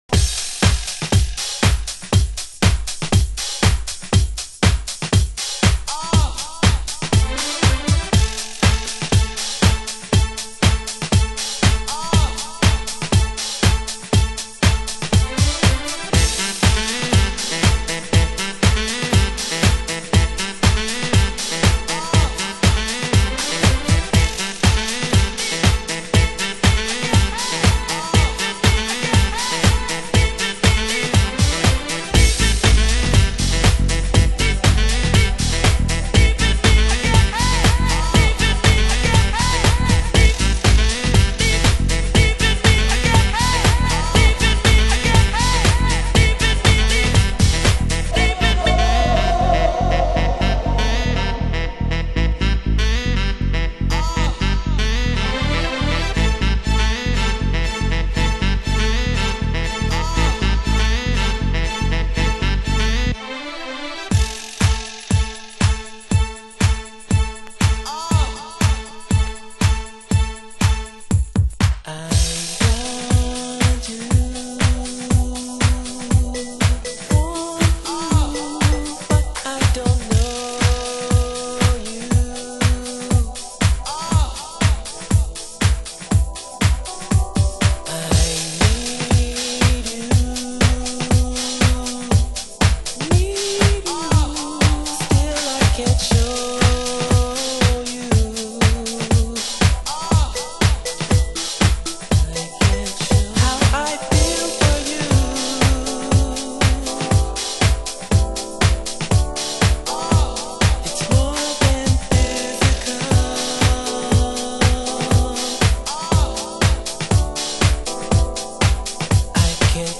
HOUSE MUSIC